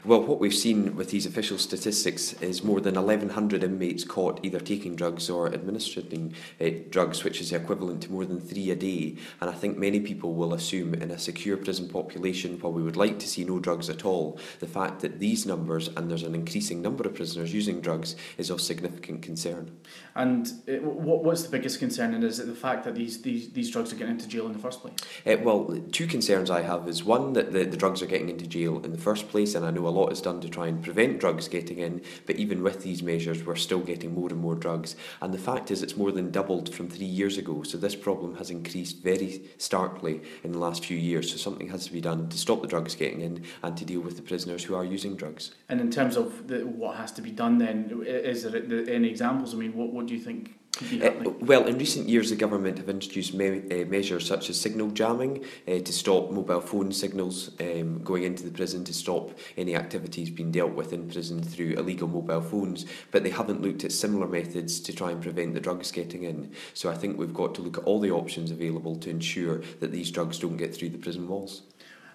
Douglas Ross MSP speaks